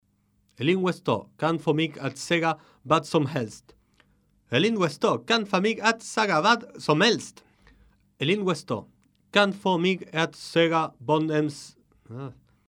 Swedish
Of course, I have no idea how any of the two languages is actually spoken, I'm just reading and making up as I go.